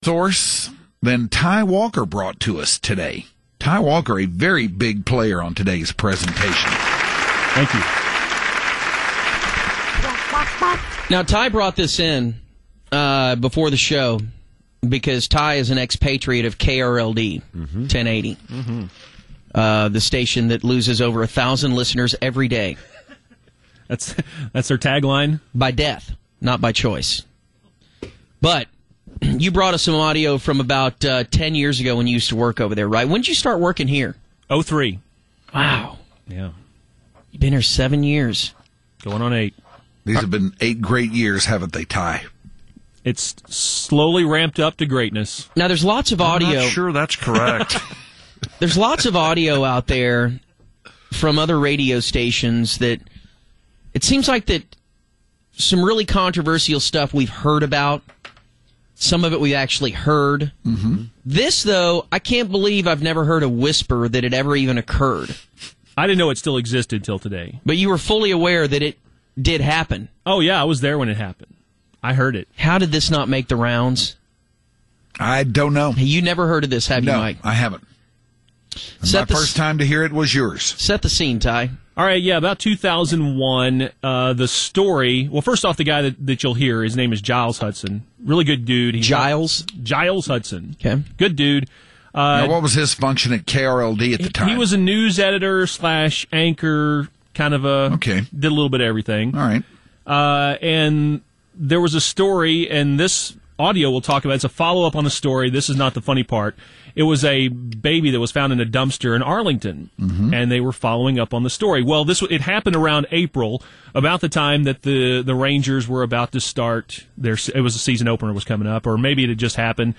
They do a good build up and explanation of how it all happened back then, but the recovery of the guy telling the story is a point of enjoyment for the Hardline.